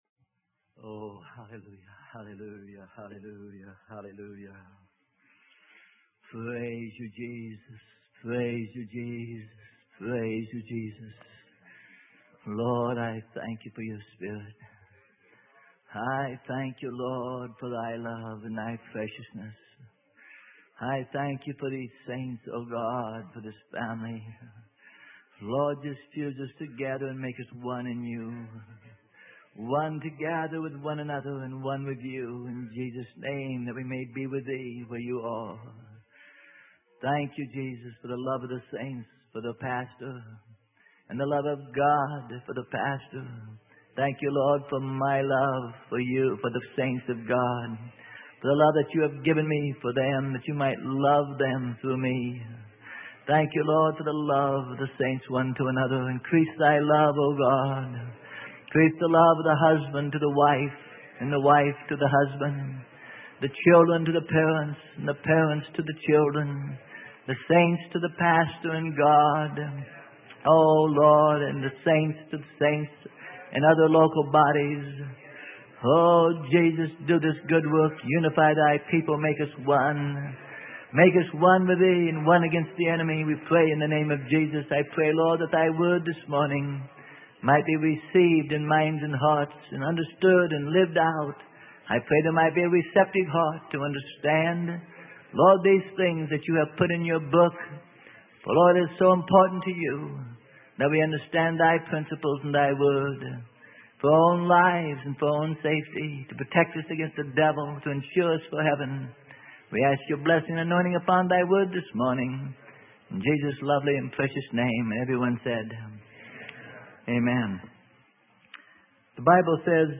Sermon: The Balance on Authority - Part 5 - Freely Given Online Library